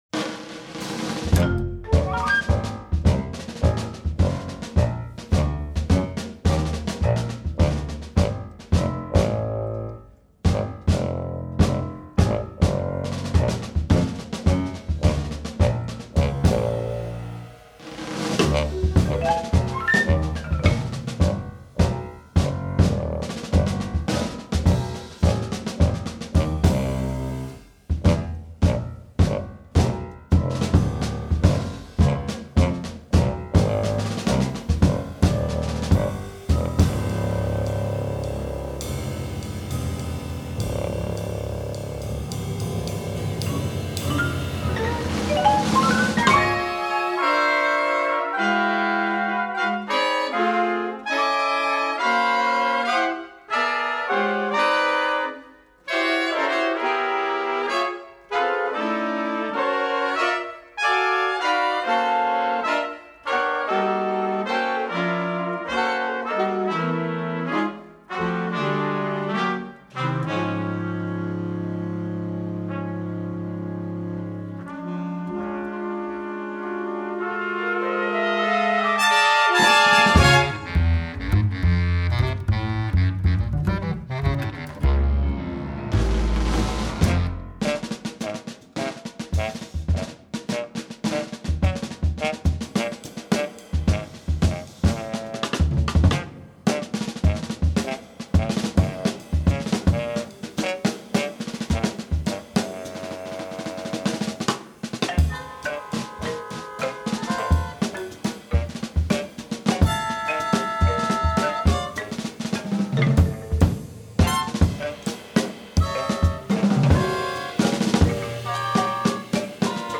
piano
Vibraphone